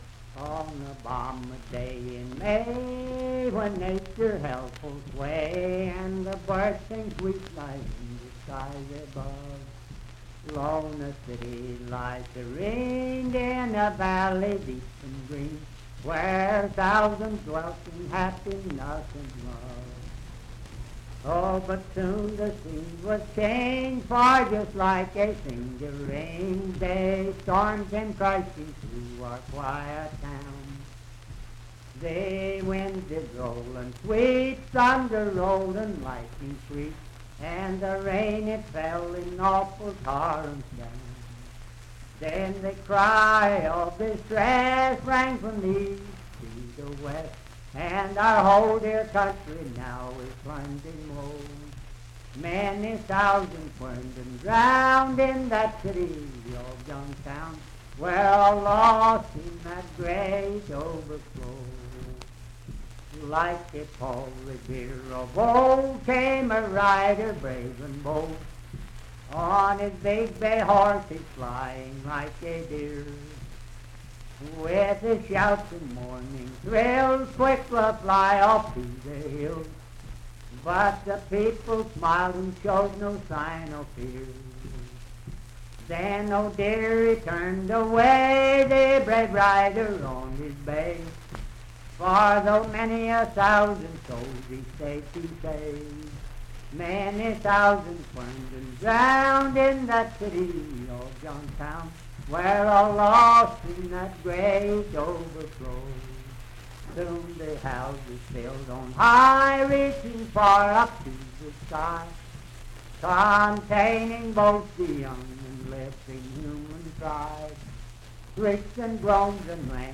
Unaccompanied vocal music and folktales
Verse-refrain 6(6w/R).
Voice (sung)
Parkersburg (W. Va.), Wood County (W. Va.)